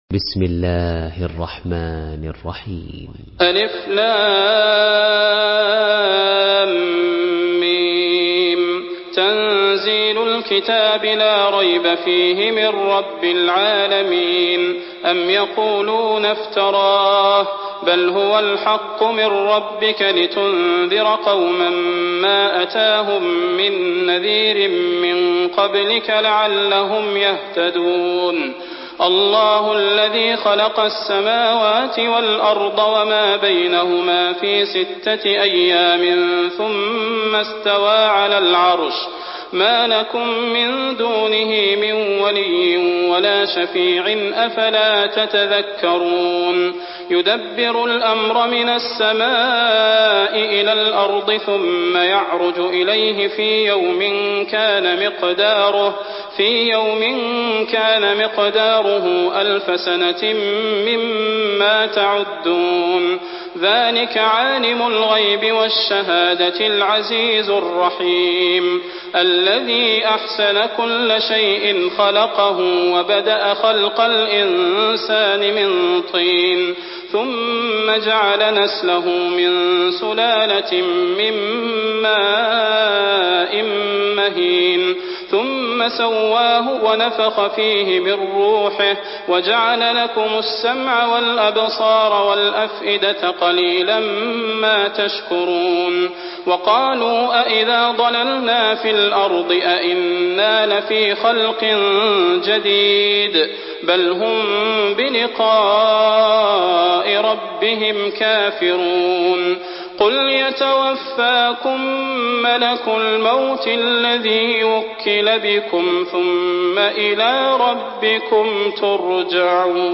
Surah As-Sajdah MP3 by Salah Al Budair in Hafs An Asim narration.
Murattal Hafs An Asim